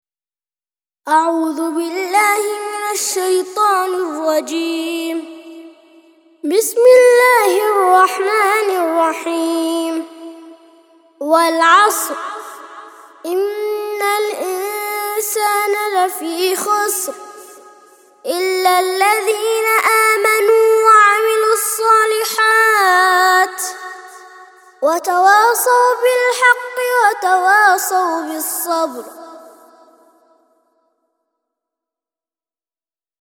103- سورة العصر - ترتيل سورة العصر للأطفال لحفظ الملف في مجلد خاص اضغط بالزر الأيمن هنا ثم اختر (حفظ الهدف باسم - Save Target As) واختر المكان المناسب